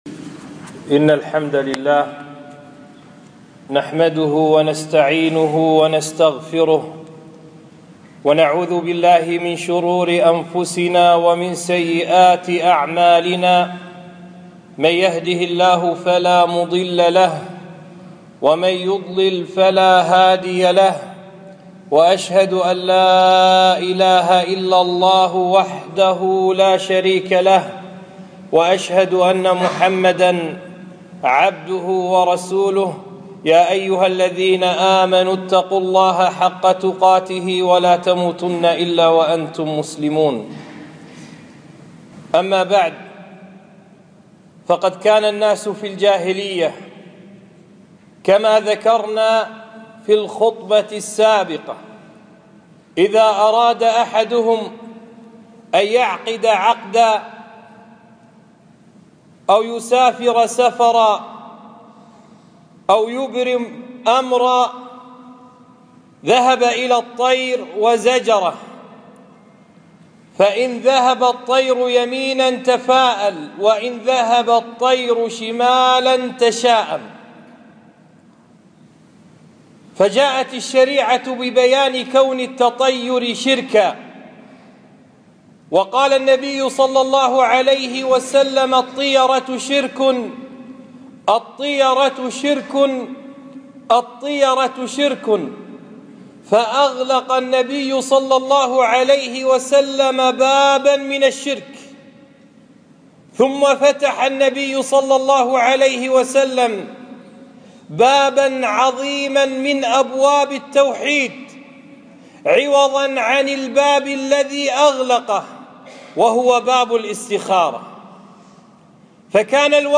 خطبة - صلاة الاستخارة